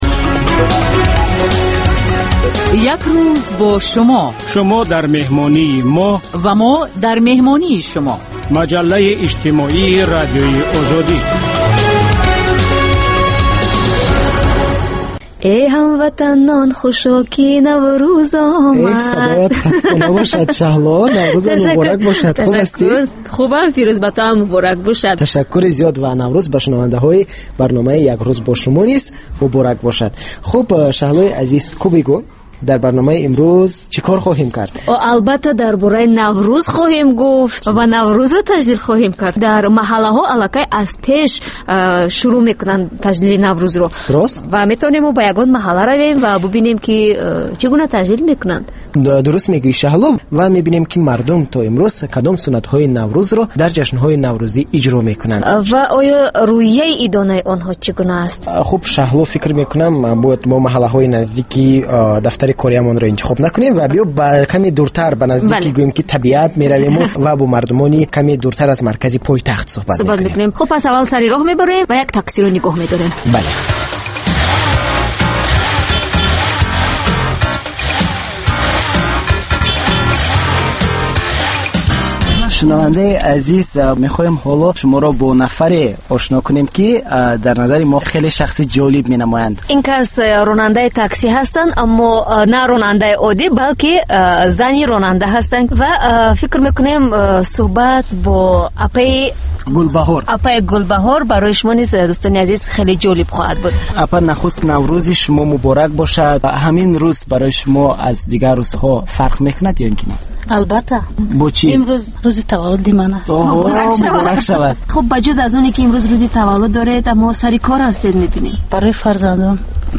"Як рӯз бо Шумо" барои дарки рӯзгори Шумо. Маҷаллаи ғайриодӣ, ки ҳамзамон дар шакли видео ва гуфтори радиоӣ омода мешавад.